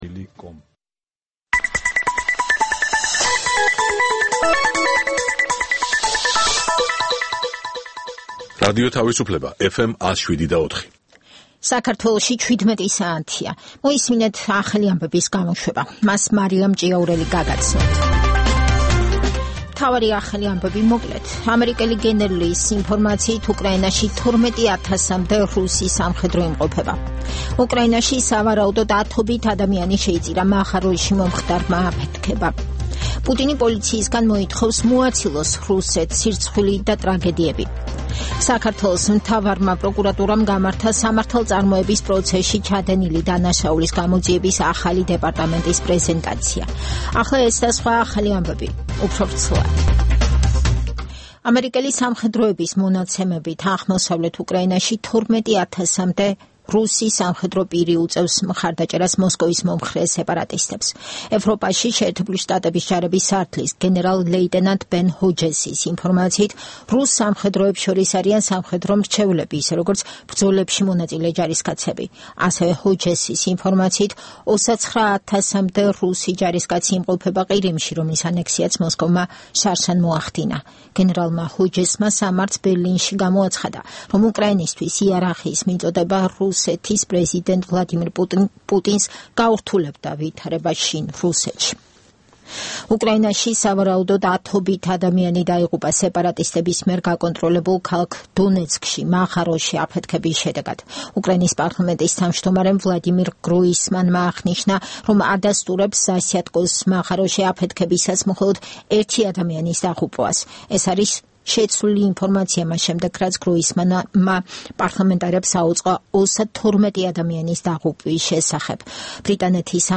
ცნობილ ექსპერტებთან ერთად, გადაცემაში მონაწილეობენ საზოგადოებისთვის ნაკლებად ცნობილი სახეები, ახალგაზრდა სამოქალაქო აქტივისტები. გამოყენებულია "რადიო თავისუფლების" საარქივო მასალები, რომელთაც გადაცემის სტუმრები "დღევანდელი გადასახედიდან" აფასებენ. გადაცემა ეთერში გადის ყოველ სამუშაო დღეს, 17:00-ზე, სიხშირეზე FM 107,4.